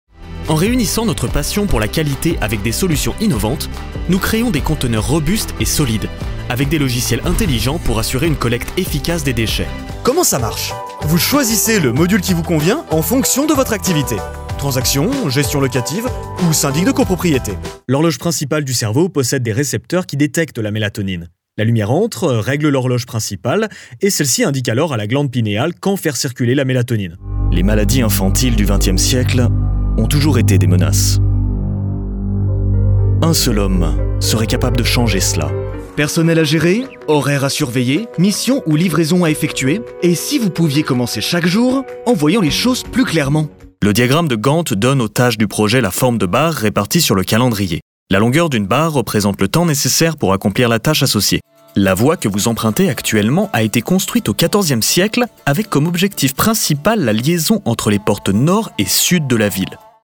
Male
Assured, Authoritative, Character, Confident, Cool, Corporate, Engaging, Friendly, Gravitas, Natural, Reassuring, Smooth, Warm, Versatile, Soft
Microphone: Audio Technica AT2020 USB+
Audio equipment: Sound-treated booth, Beyerdynamic DT250 headphones, SourceConnect Now, ipDTL, Skype